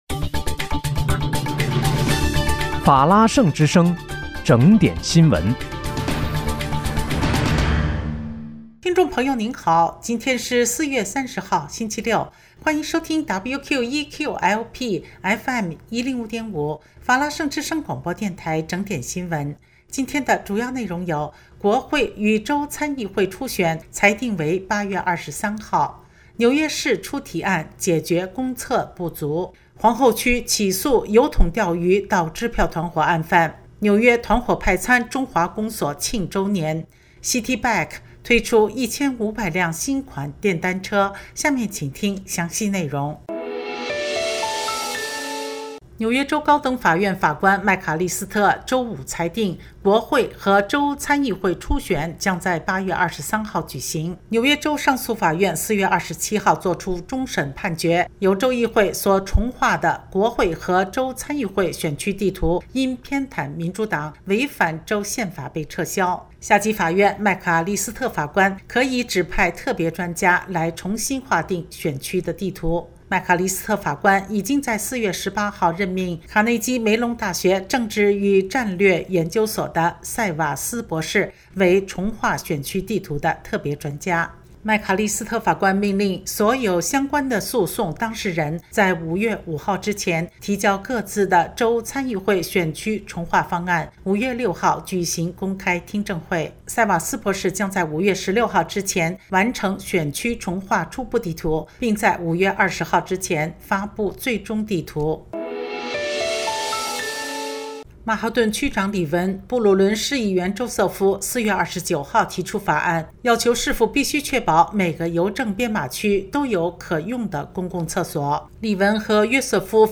4月30日（星期六）纽约整点新闻
听众朋友您好！今天是4月30号，星期六，欢迎收听WQEQ-LP FM105.5法拉盛之声广播电台整点新闻。